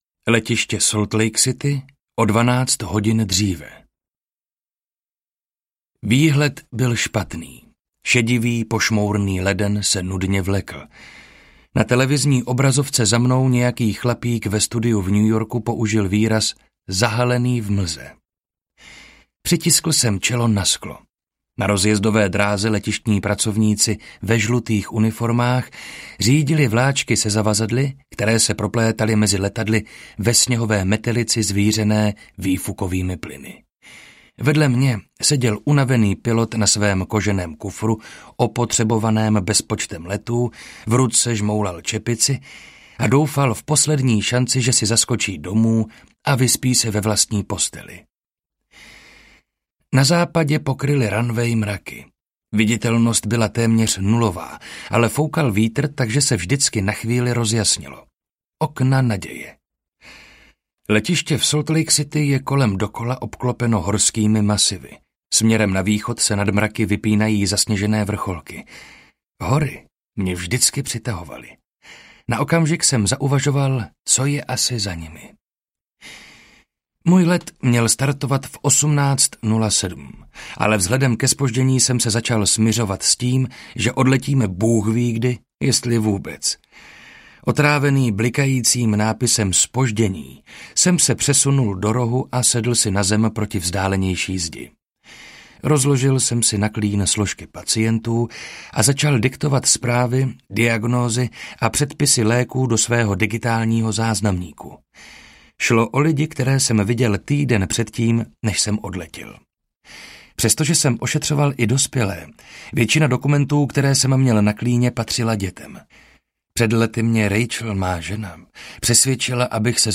Hora mezi námi audiokniha
Ukázka z knihy